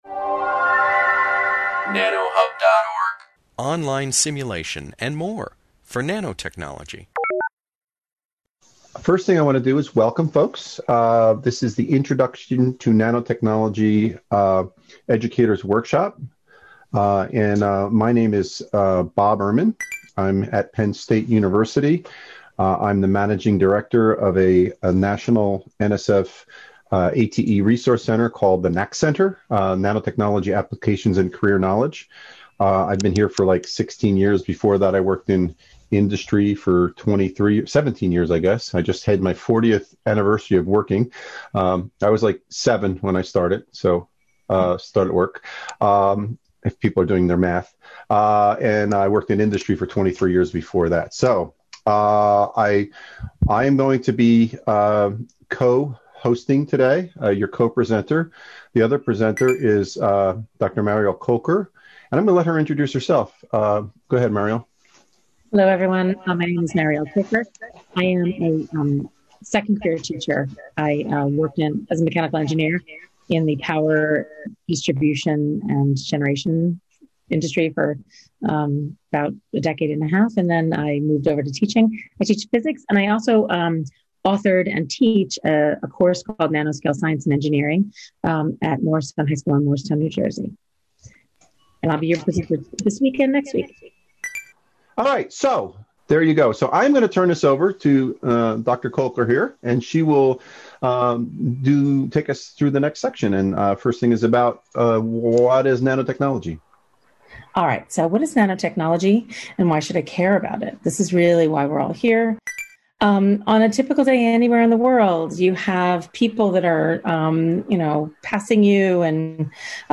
During the webinar, presenters define nanotechnology, discuss nanometers and the role of characterization in nanotechnology, and highlight the impact of size on material properties, covering aspects such as surface effects and light-matter interactions at the nanoscale.